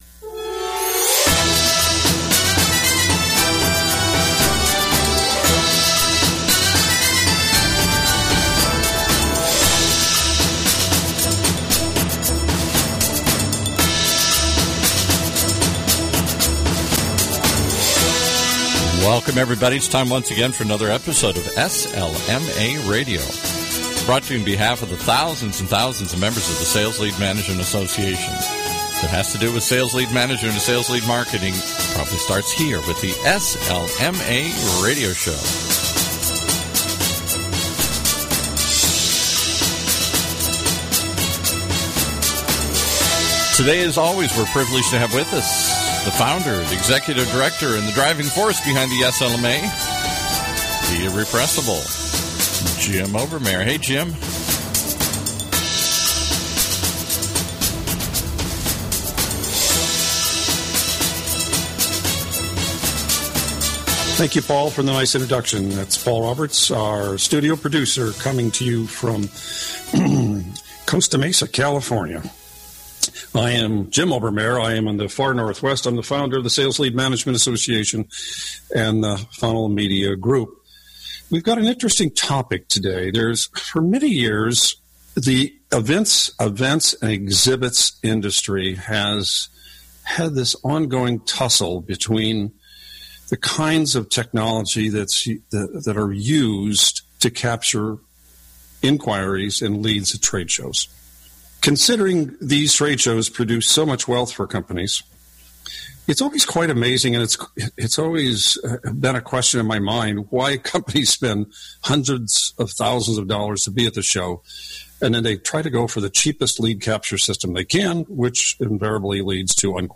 Mobile led capture seems to be gaining traction. In this interview